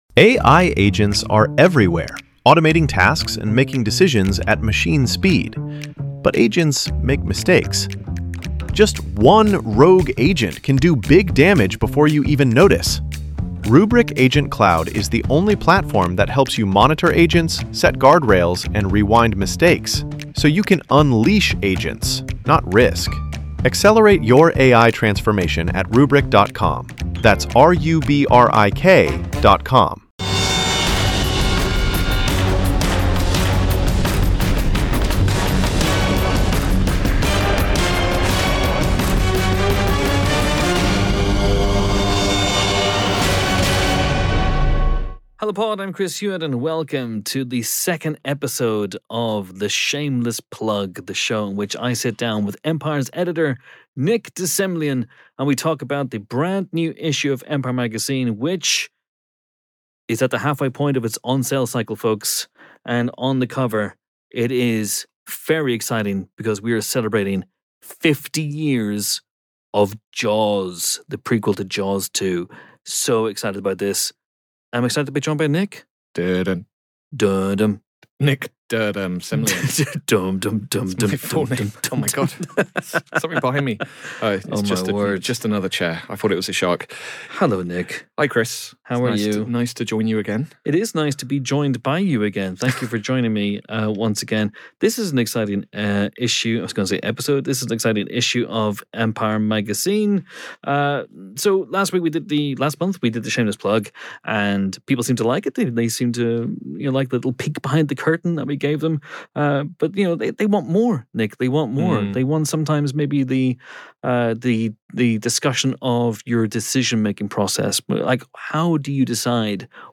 sit down in the podbooth